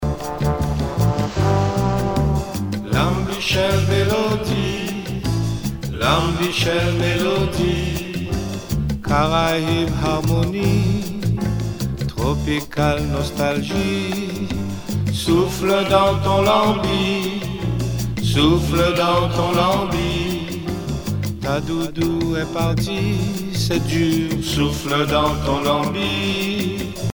danse : biguine
Pièce musicale éditée